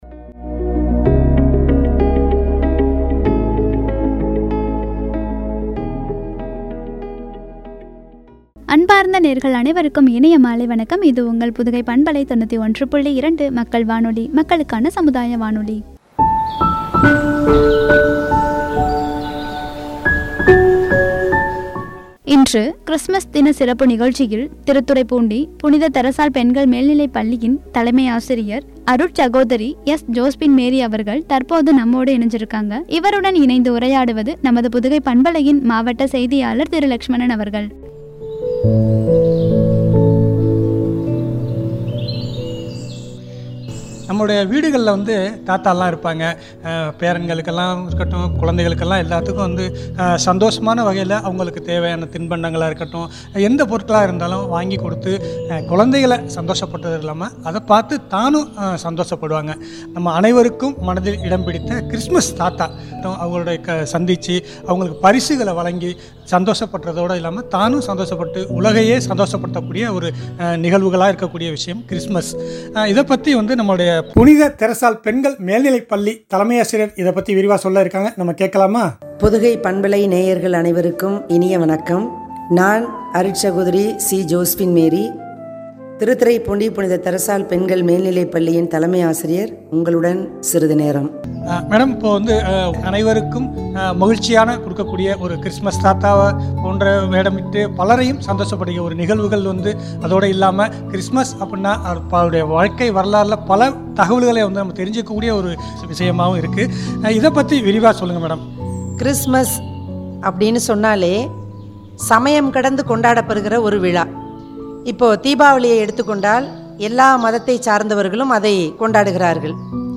“கிருஸ்துமஸ் சிறப்புகள்” என்ற தலைப்பில் வழங்கிய உரையாடல்.